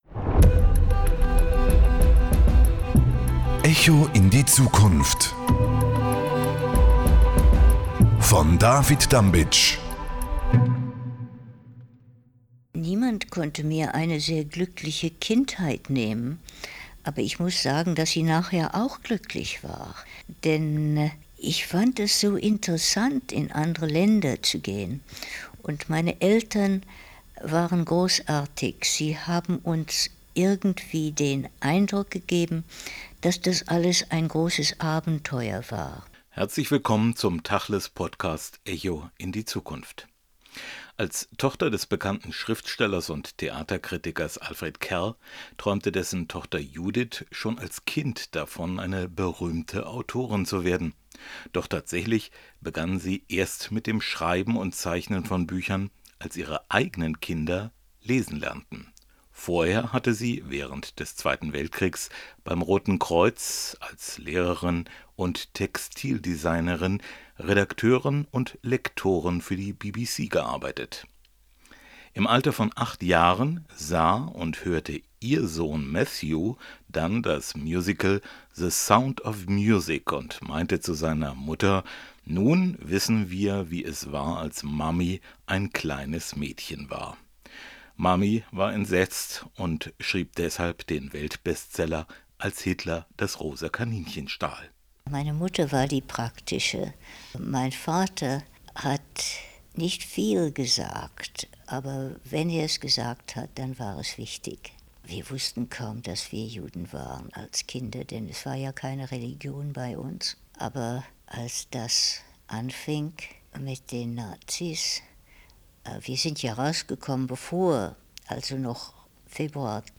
ein Gespräch mit Judith Kerr. Die Tochter des bekannten Schriftstellers und Theaterkritikers Alfred Kerr träumte schon als Kind davon eine berühmte Autorin zu werden, doch tatsächlich begann sie erst mit dem Schreiben und Zeichnen von Büchern als ihre eigenen Kinder lesen lernten. Vorher hatte sie während des Zweiten Weltkriegs beim Roten Kreuz, als Lehrerin und Textildesignerin, Redakteurin und Lektorin für die BBC gearbeitet.